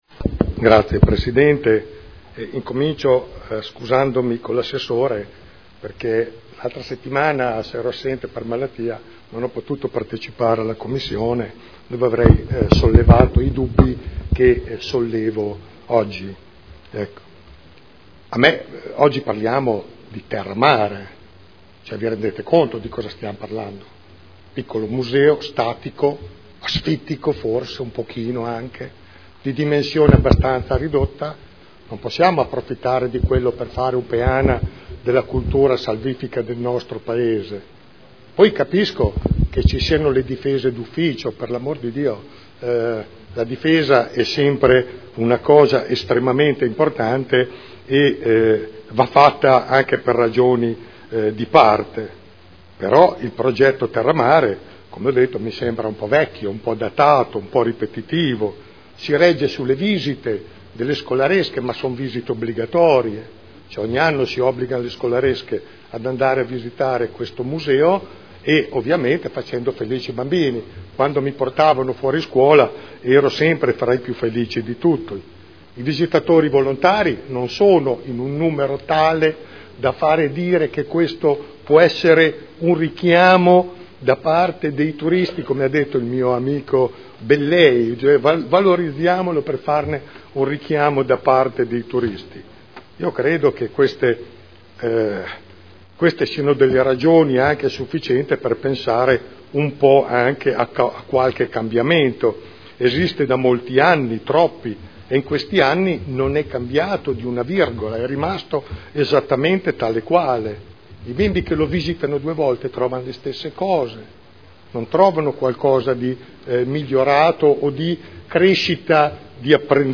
Giancarlo Pellacani — Sito Audio Consiglio Comunale
Seduta del 08/04/2013 Dibattito.